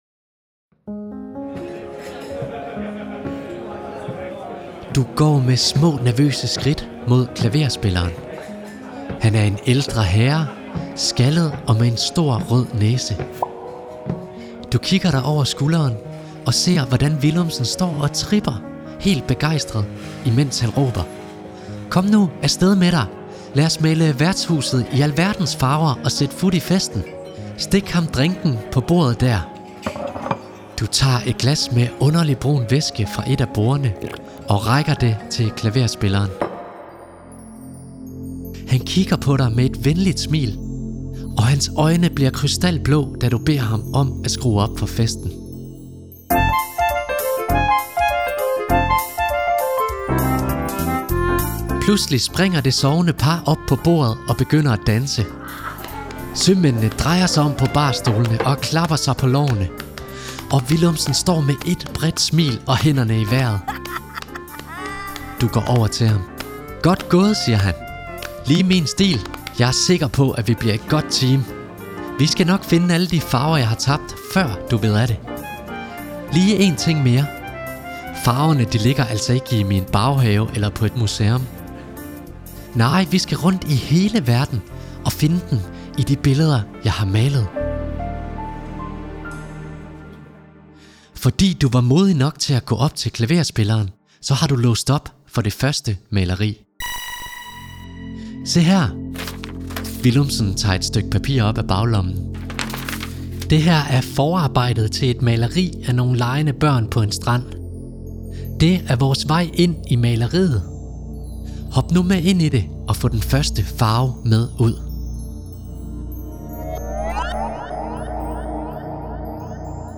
I den her lydfortælling skal du på eventyr med Willumsen.